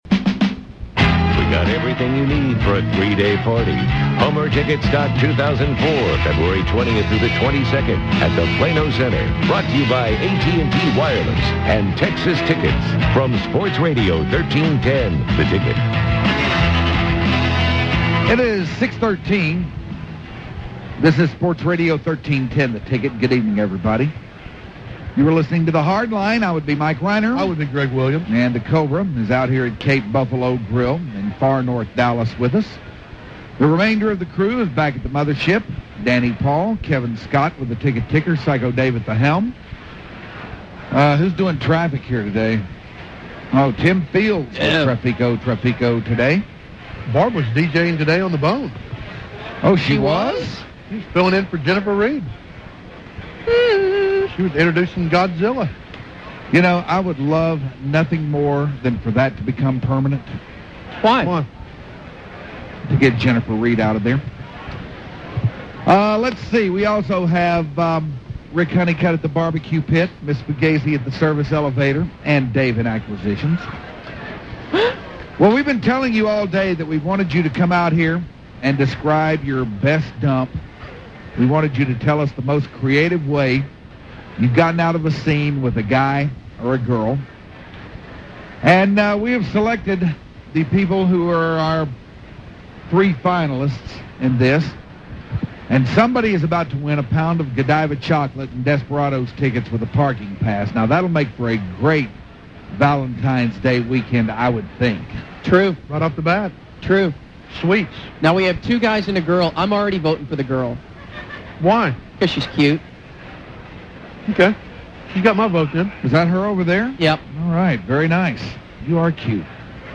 P1’S tell there best break up stories